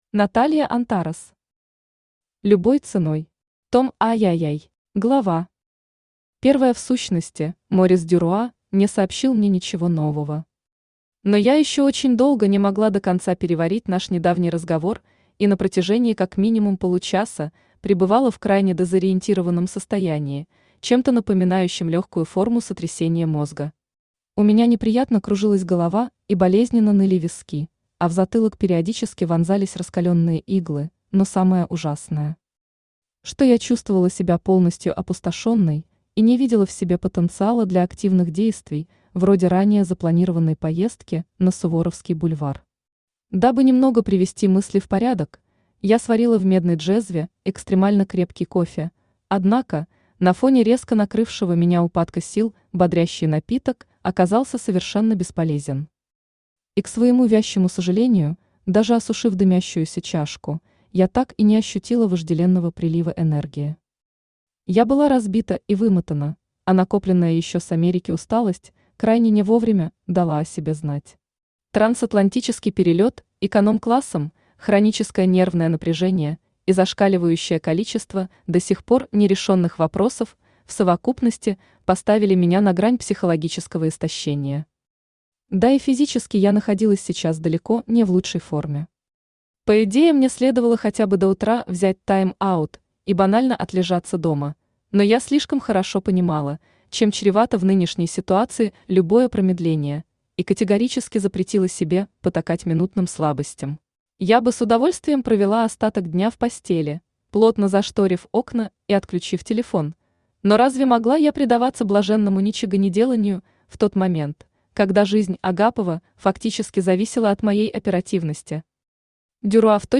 Аудиокнига Любой ценой. Том III | Библиотека аудиокниг
Том III Автор Наталья Антарес Читает аудиокнигу Авточтец ЛитРес.